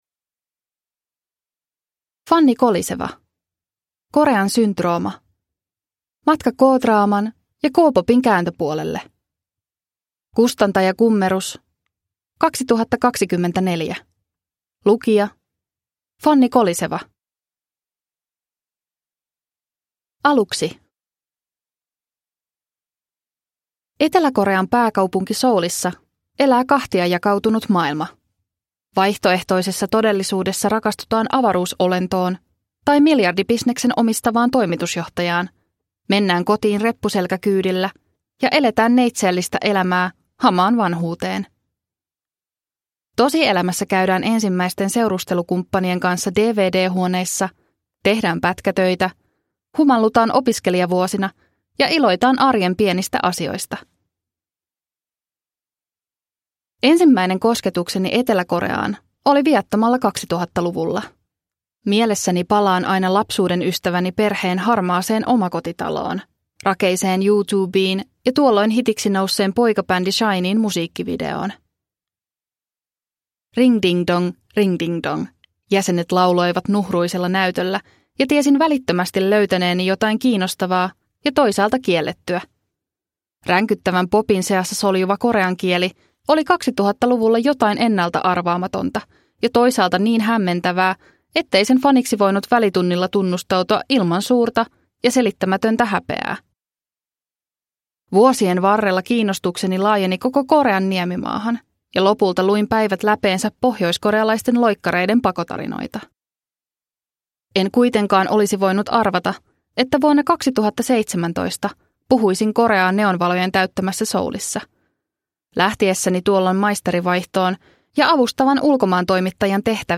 Korean syndrooma – Ljudbok